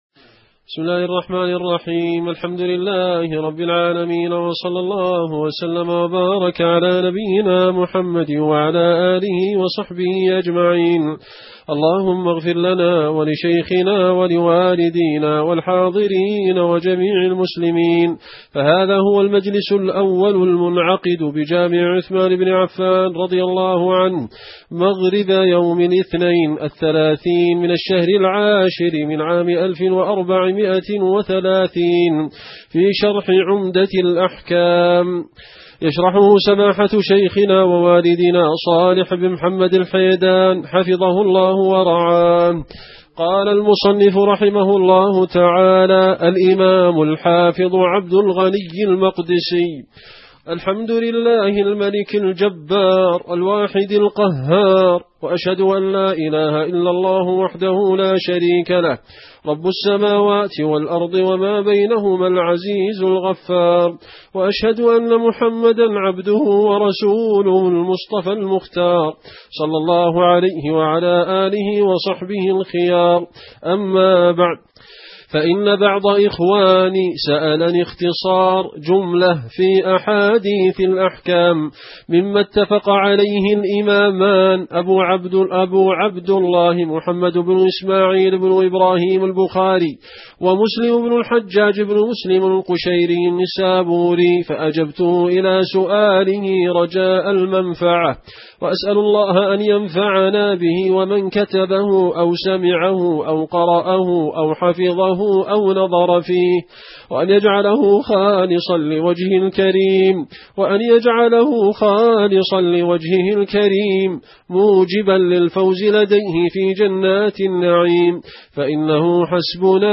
عمدة الأحكام في معالم الحلال والحرام عن خير الأنام شرح الشيخ صالح بن محمد اللحيدان الدرس 1